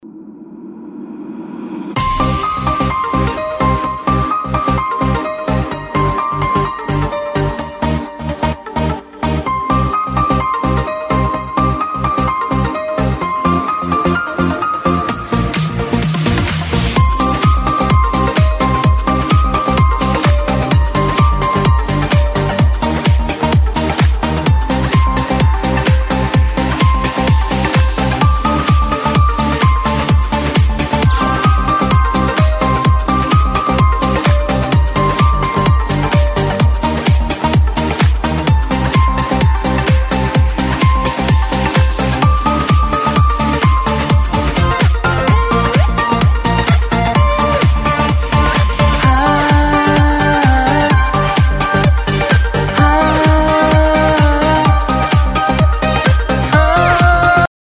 • Качество: 256, Stereo
казахские